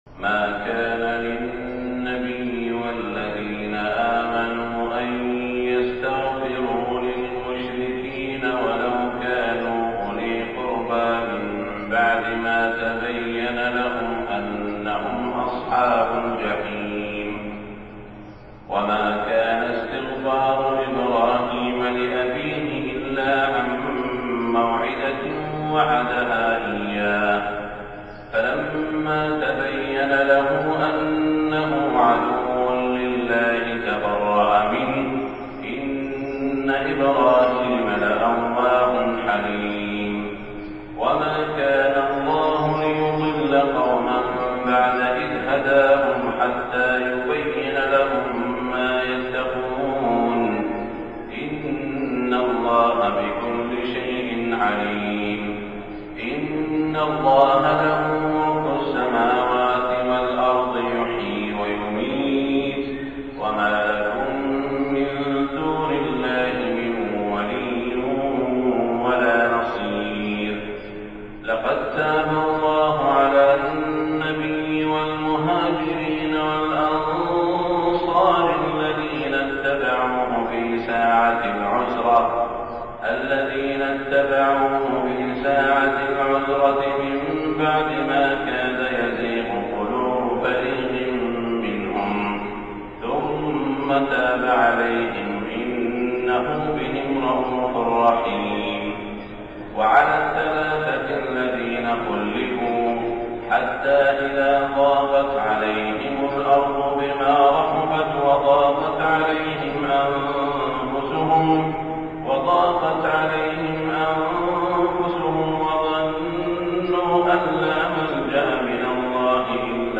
صلاة الفجر 8 شوال 1427هـ من سورة التوبة > 1427 🕋 > الفروض - تلاوات الحرمين